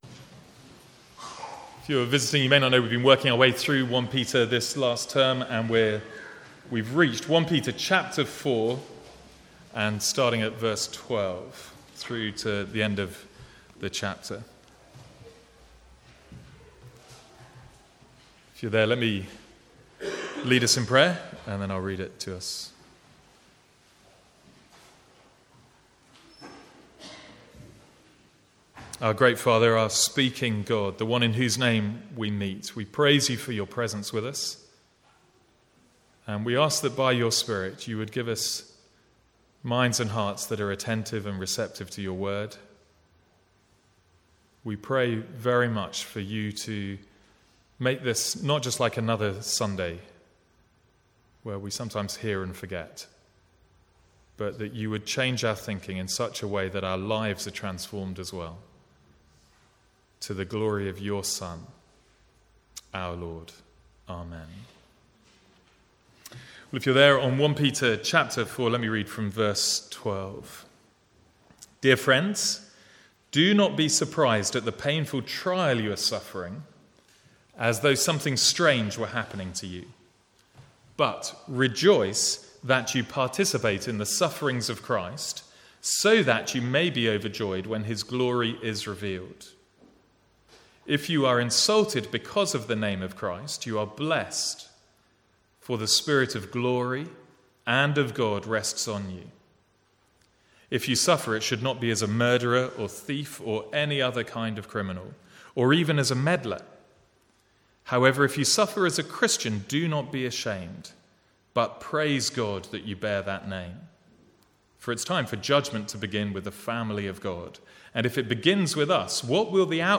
From the Sunday morning series in 1 Peter.